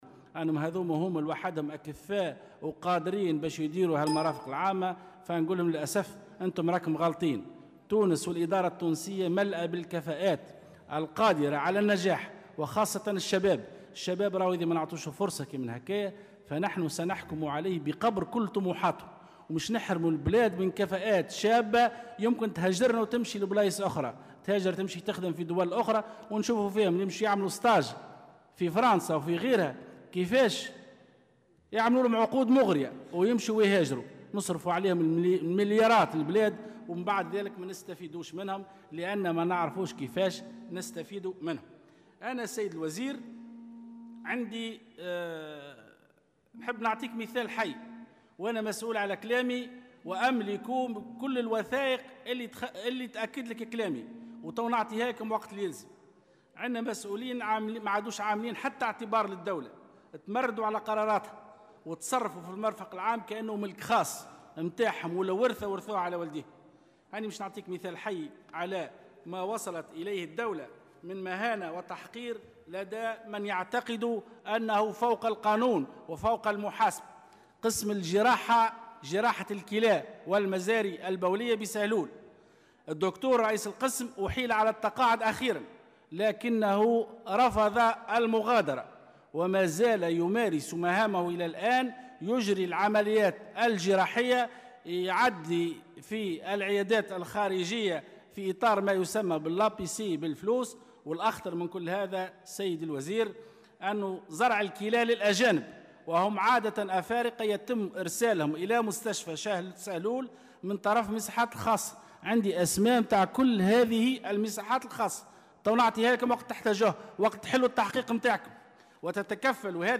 وجاء ذلك خلال جلسة برلمانية عامة بحضور وزير التنمية والاستثمار والتعاون الدولي، زياد العذاري، وباشراف نائب رئيس مجلس نواب الشعب عبد الفتاح مورو.